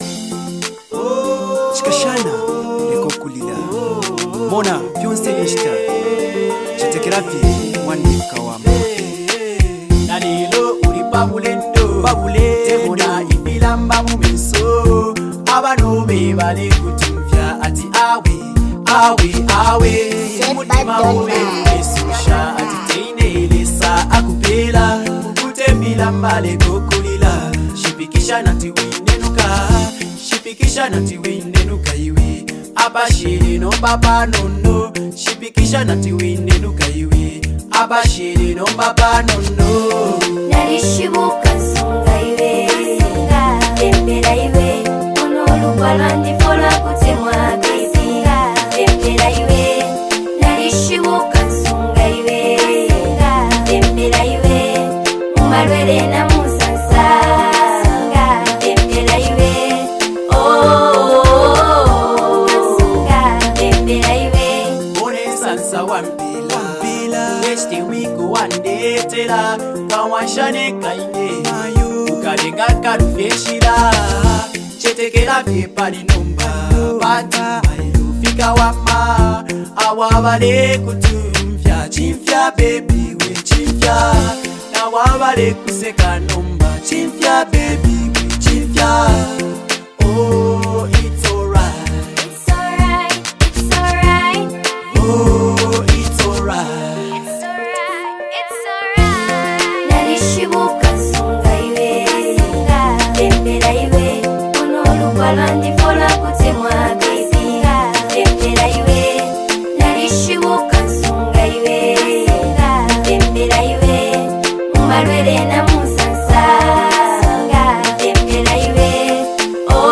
calm and soothing voice